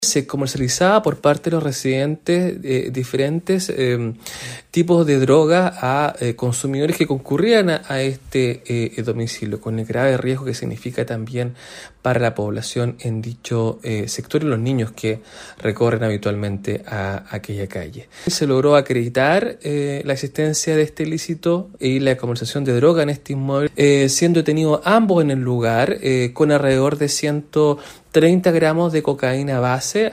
Así lo explicó el fiscal de Osorno, Carlos Delgado, quien detalló que, tras su captura, la mujer y el hombre fueron formalizados por el delito de tráfico de drogas en pequeñas cantidades y tenencia ilegal de munición.